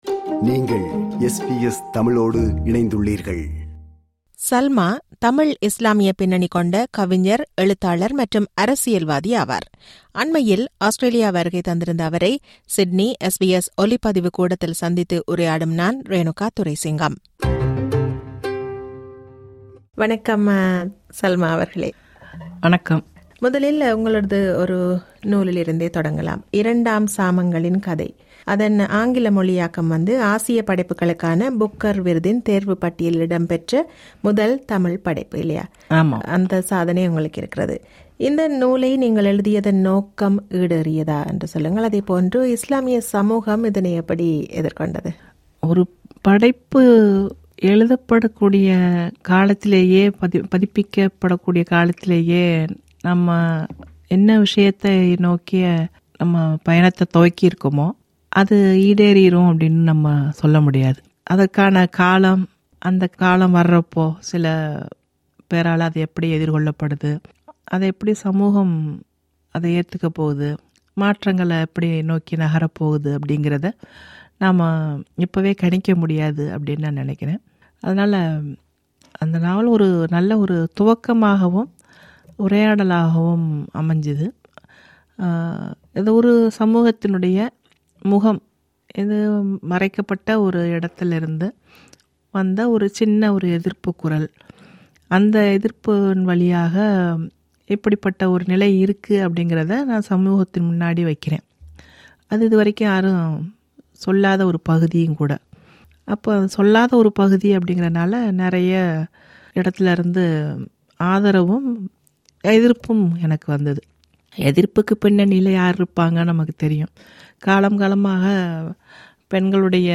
ராஜாத்தி சல்மா, தமிழ் இஸ்லாமிய பின்னணி கொண்ட கவிஞர், எழுத்தாளர் மற்றும் அரசியல்வாதியாவார். அண்மையில் ஆஸ்திரேலியா வருகை தந்திருந்த அவரை சிட்னி SBS ஒலிப்பதிவு கூடத்தில் சந்தித்து உரையாடுகிறார்